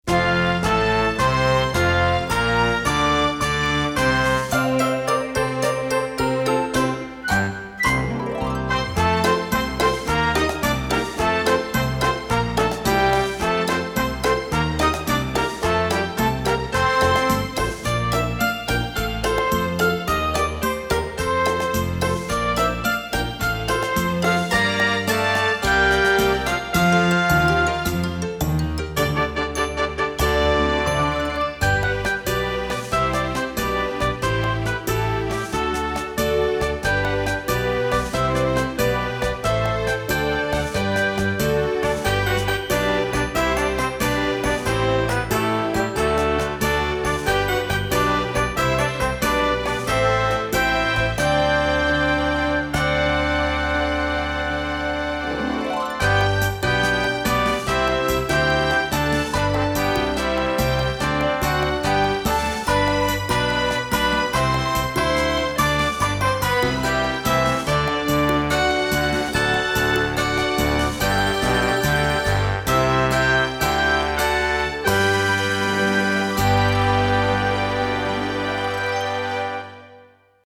Voicing: Conductor / Piano